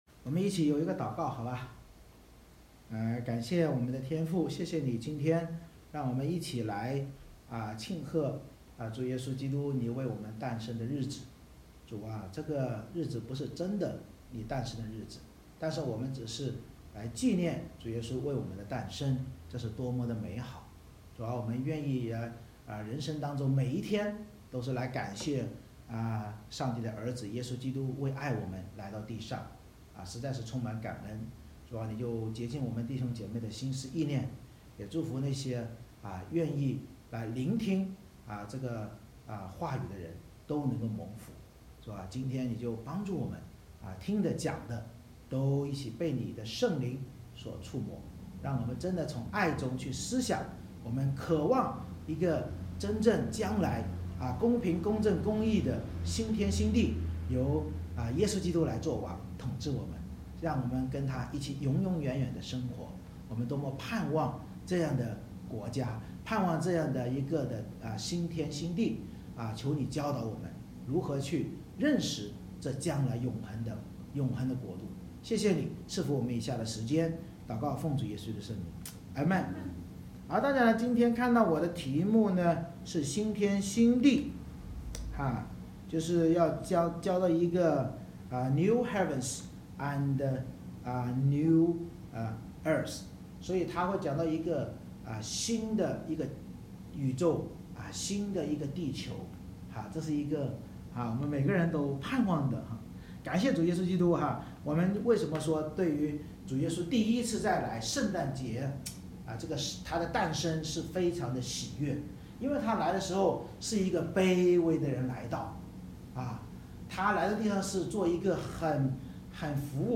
以赛亚书Isaiah65:1-25 Service Type: 圣诞主日 神藉着先知以赛亚预言将来弥赛亚千禧年并新天新地的景象，启示我们当弥赛亚再临时将按公平审判万民，山羊即恶人必进入永火，而绵羊即义人必进入新天新地。